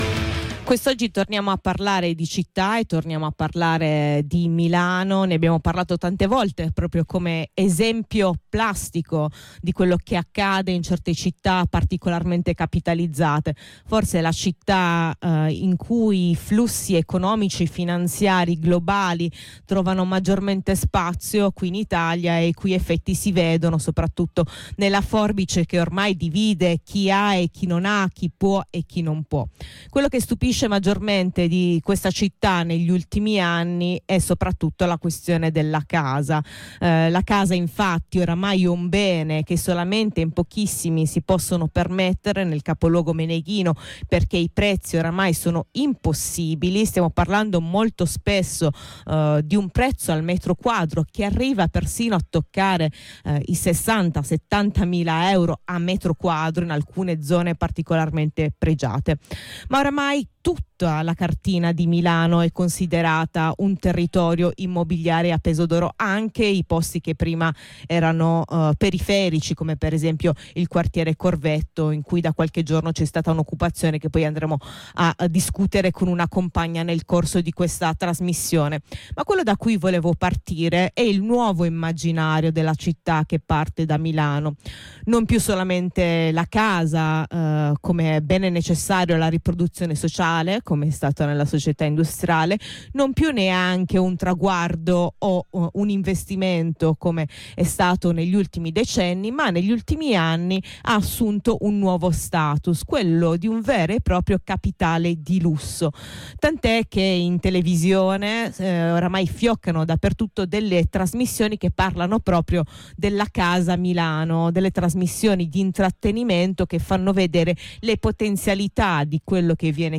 nella seconda parte di trasmissione una compagna ci racconta invece i tentativi di resistenza per avere un tetto sopra la testa e di un’occupazione avvenuta qualche giorno fa.